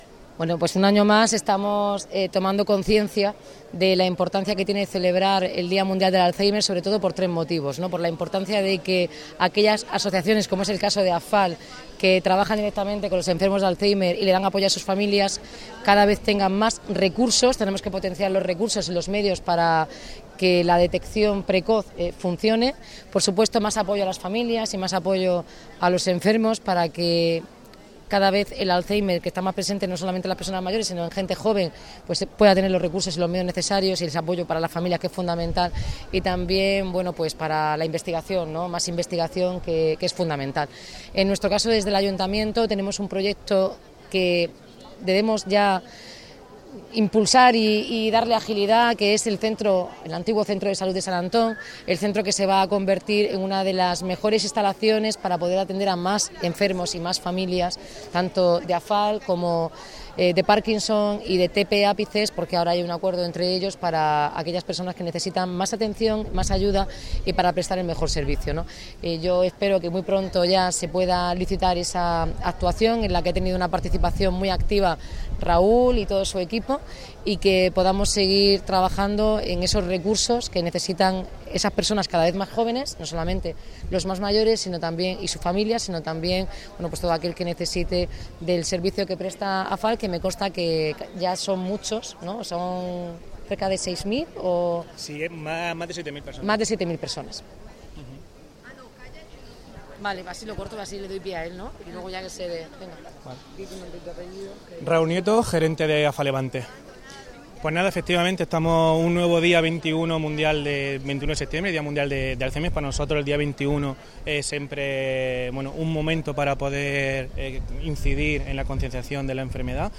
Enlace a Declaraciones Noelia Arroyo